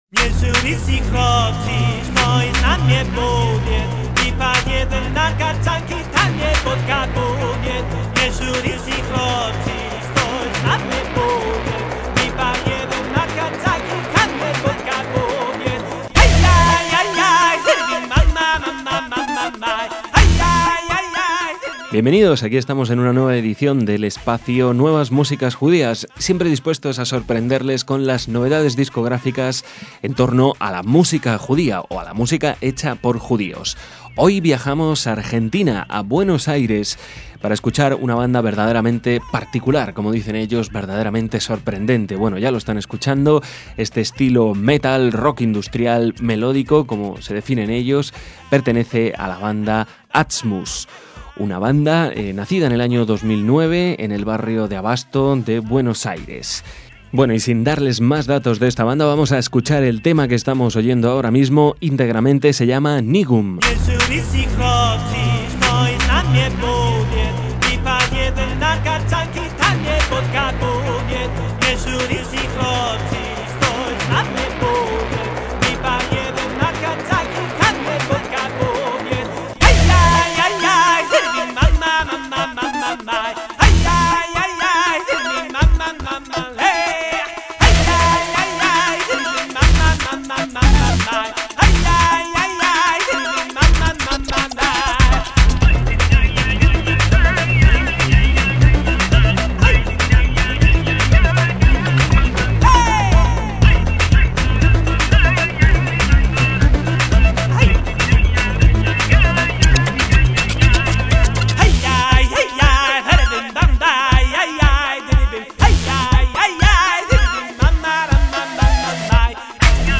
guitarra eléctrica
batería